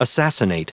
Prononciation du mot assassinate en anglais (fichier audio)
Prononciation du mot : assassinate